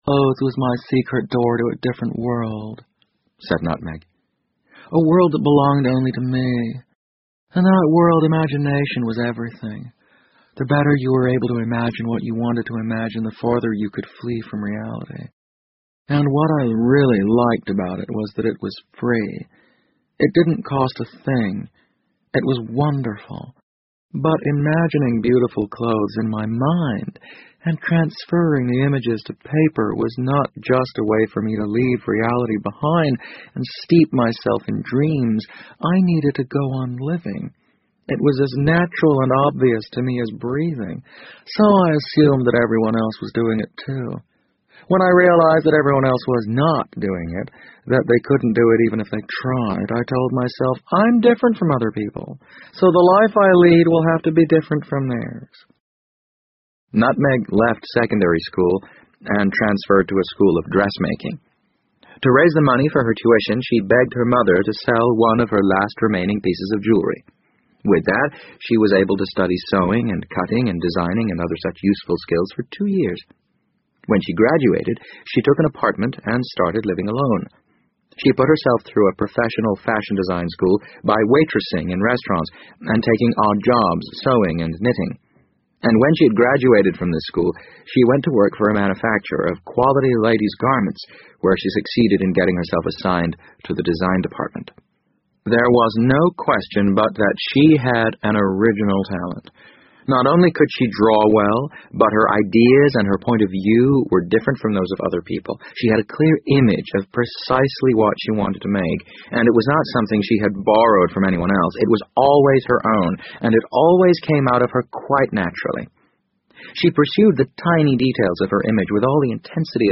BBC英文广播剧在线听 The Wind Up Bird 012 - 9 听力文件下载—在线英语听力室